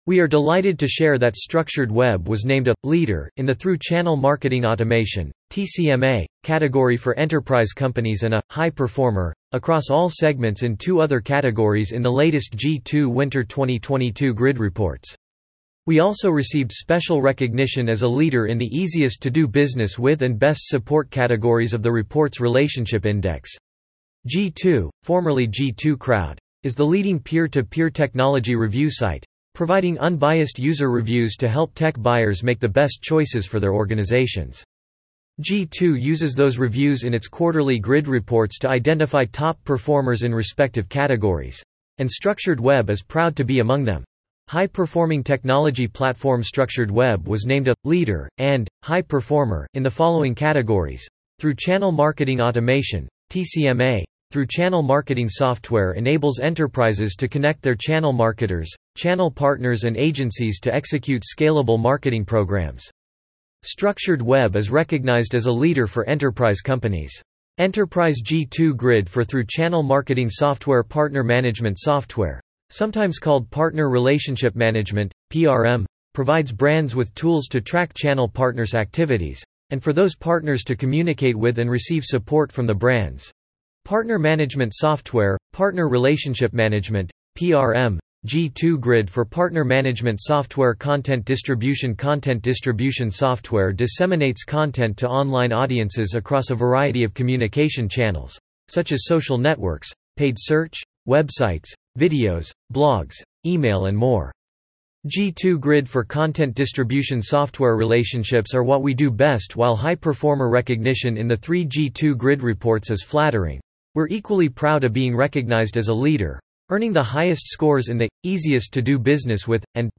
Blogcast